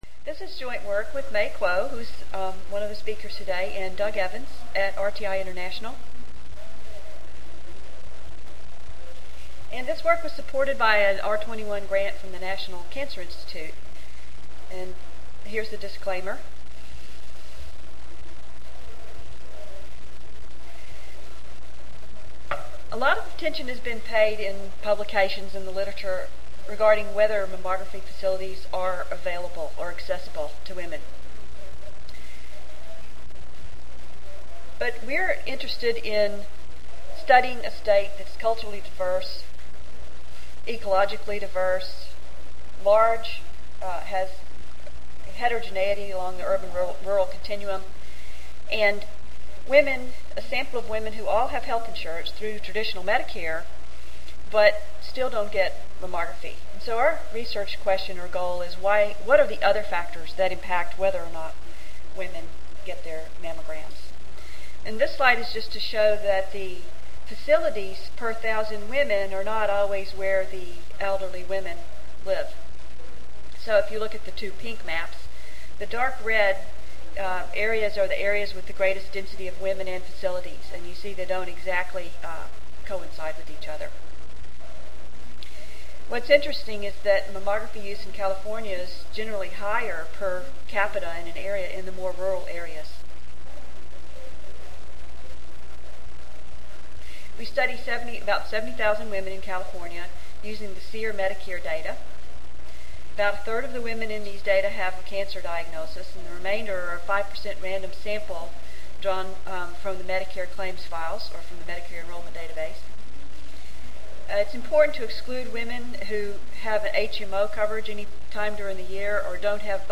5086.0 Compositional or Contextual Factors and Women's Health Disparities Wednesday, November 7, 2007: 8:30 AM Oral This organized panel combines four papers that all address, in some fashion, women�s health disparities, and all focus on the fact that many factors are instrumental in determining healthcare outcomes.